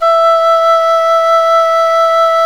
SULING VIB03.wav